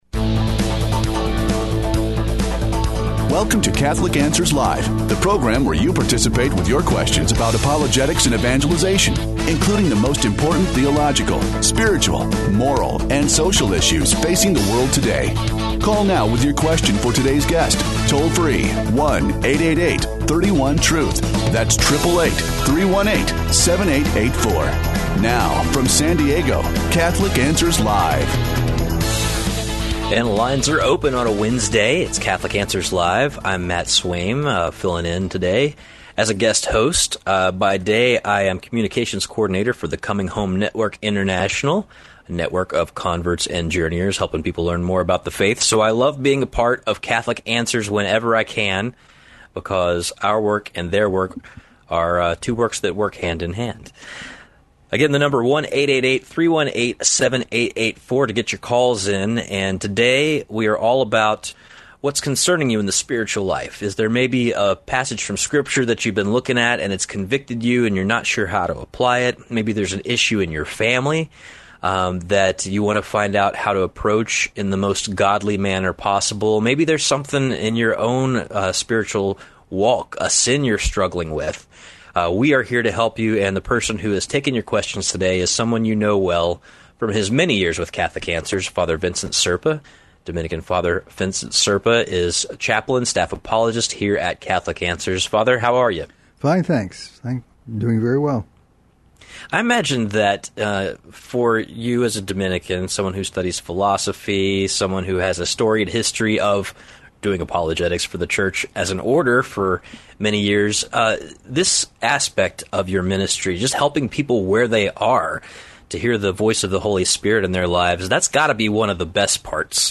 takes questions of a pastoral nature in this hour devoted to the care of souls, growth in the spiritual life...